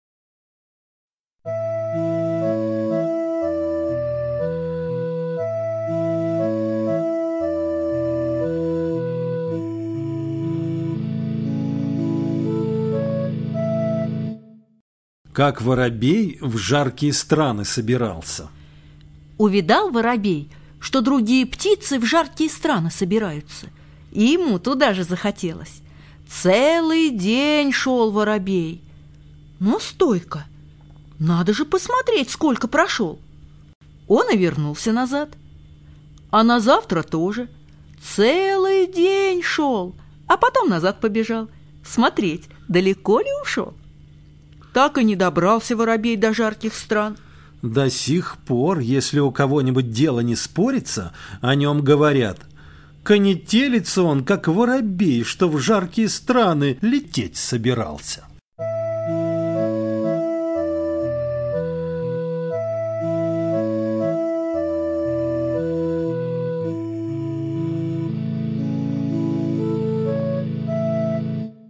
Слушайте онлайн Как воробей в жаркие страны собирался - латышская аудиосказка.